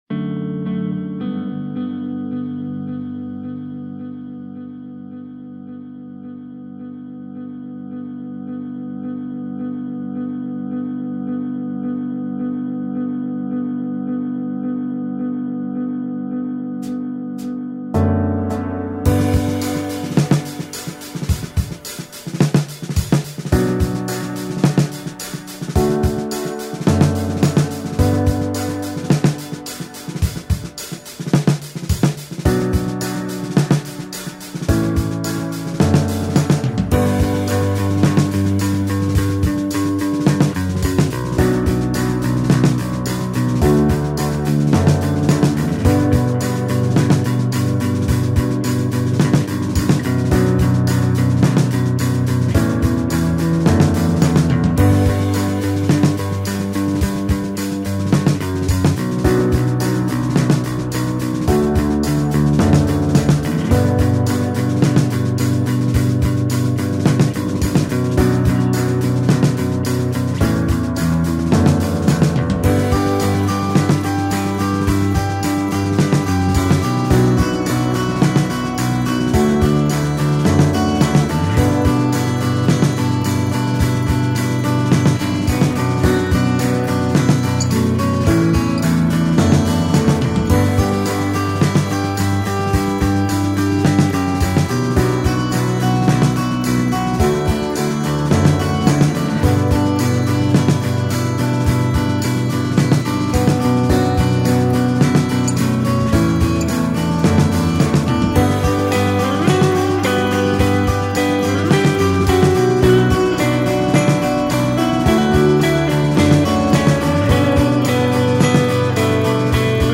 beautifully produced mostly instrumental song